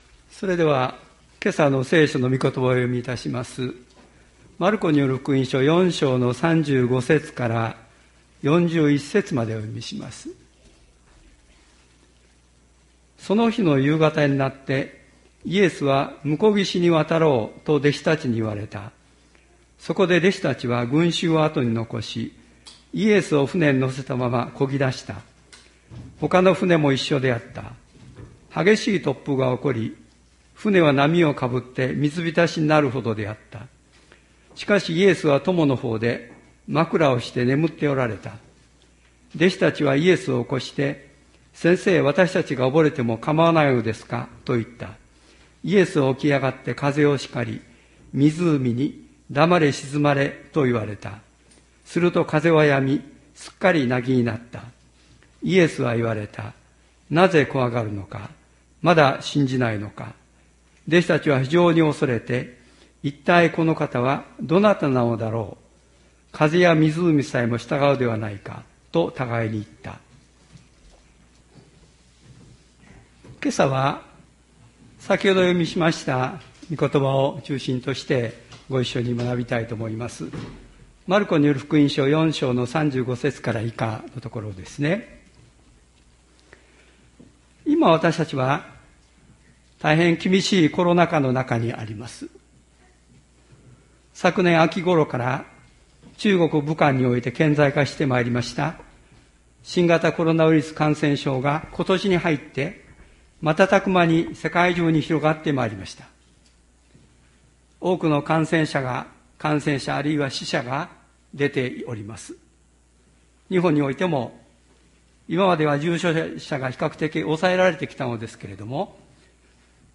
千里山教会 2020年11月29日の礼拝メッセージ。